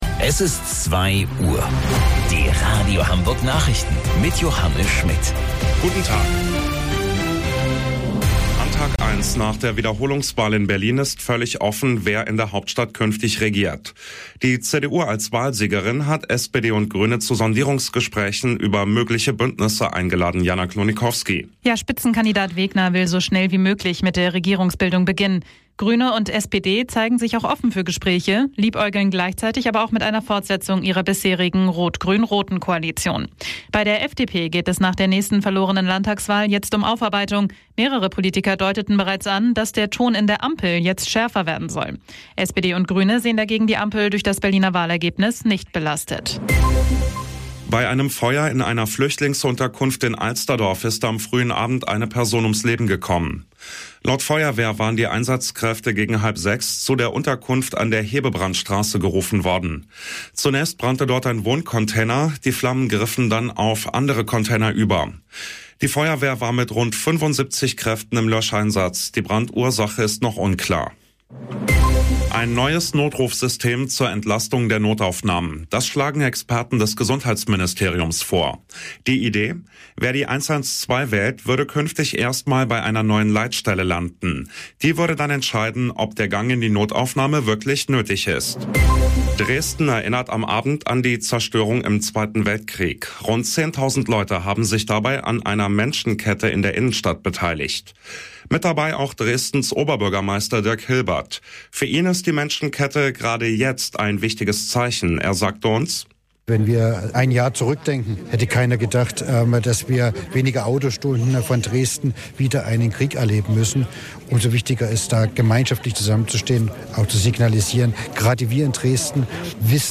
Radio Hamburg Nachrichten vom 12.09.2022 um 15 Uhr - 12.09.2022